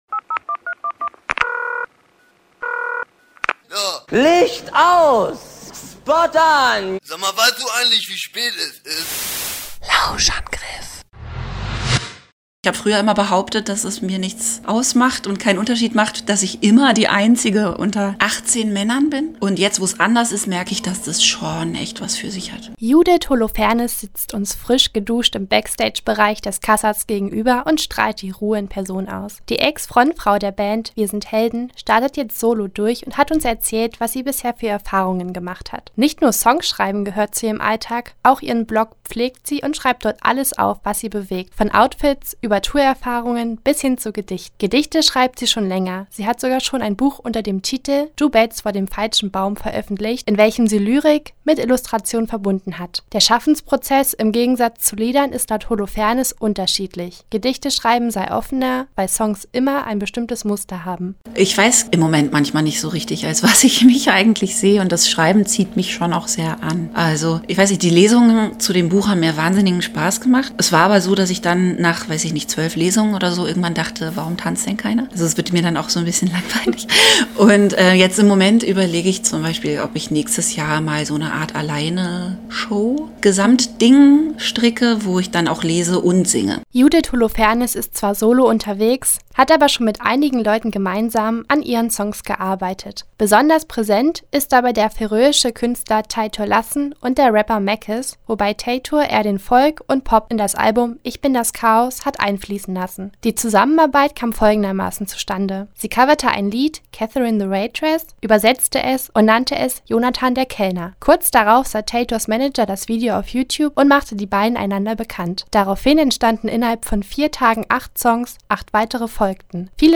Wir haben sie im Kassablanca interviewt und sie – unter anderem – gefragt, wie sie den Sprung zur Solokünstlerin gemeistert hat.